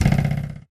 eat.ogg